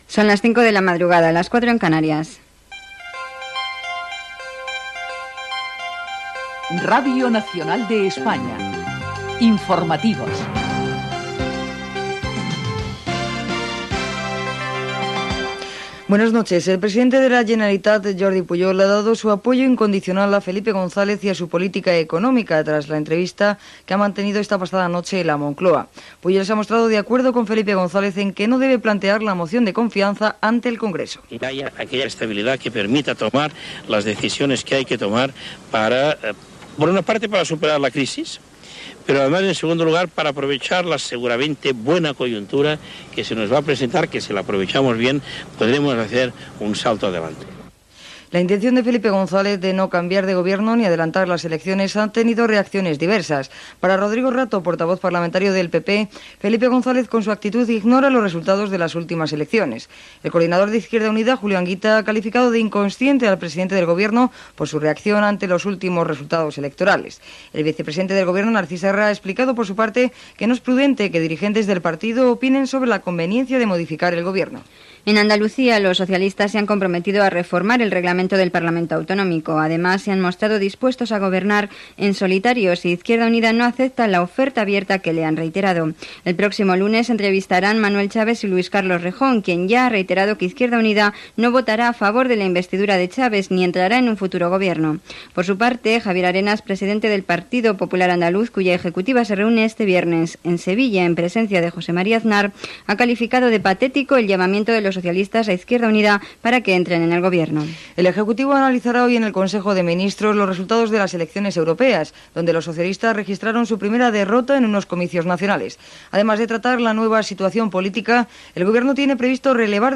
Hora, careta dels serveis informatius, el president de la Generalitat Jordi Pujol dona suport a Felipe González, reforma del Parlament andalús, el govern espanyol analitza el resultat de les eleccions europees.
Informatiu